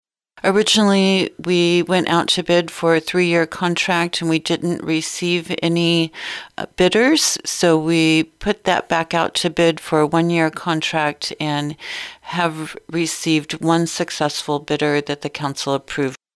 A hay ground lease at the new industrial park off of Route V was approved by the Chillicothe City Council. City Administrator Roze Frampton explains.